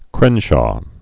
(krĕnshô) also cran·shaw (krăn-)